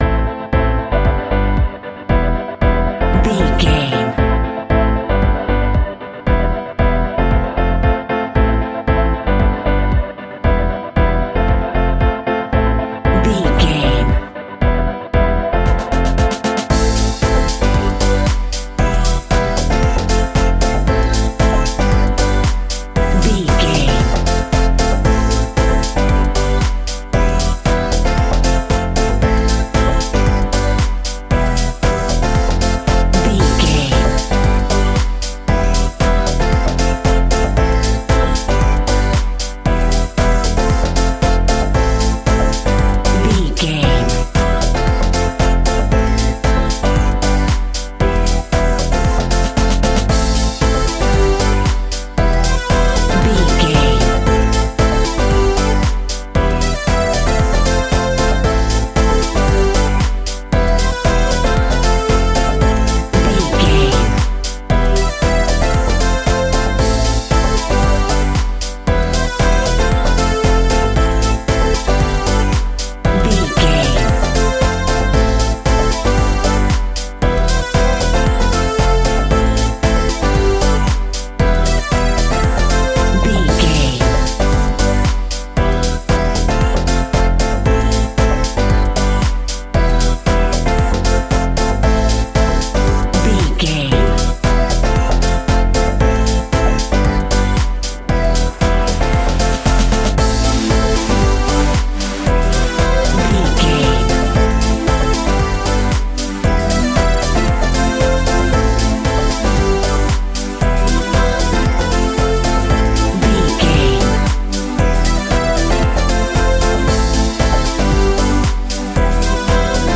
1990s Funky House Sounds.
Aeolian/Minor
groovy
uplifting
energetic
piano
bass guitar
drum machine
synthesiser
upbeat
instrumentals
funky guitar